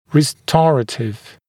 [rɪ’stɔrətɪv][ри’сторэтив]восстановительный, реставрационный, укрепляющий, тонизирующий